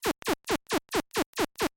合成器声音 " 13.06.07 nip53 005b
标签： 最小 repeti重刑 合成器 节奏 合成器 击败
声道立体声